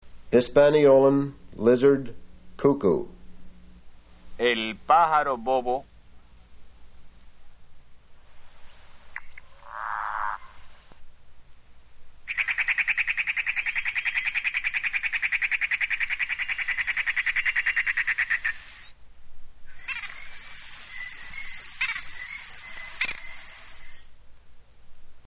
Bird Sounds from Hispaniola
Hisp-Lizard-Cuckoo
Hisp-Lizard-Cuckoo.mp3